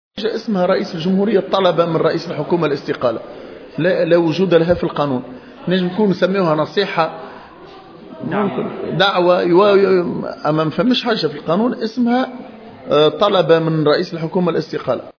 واعتبر مخلوف، في تصريح لمراسل الجوهرة أف أم، إن ما قام به رئيس الجمهورية يمكن تصنيفه في إطار الدعوة أو النصيحة، وأنه لا يمكن بأي حال من الأحوال اعتباره طلبا رسميا.